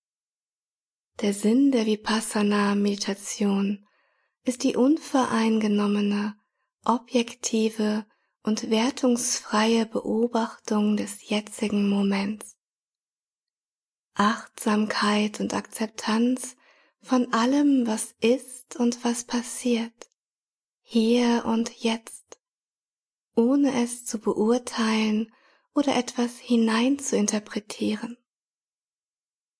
1. Vipassana Meditation - Länge 13:59 Minuten
Diese 6 geführten Meditationen unterstützen Sie auf Ihrem Weg der Achtsamkeit.
Alle Meditationen ohne Musik.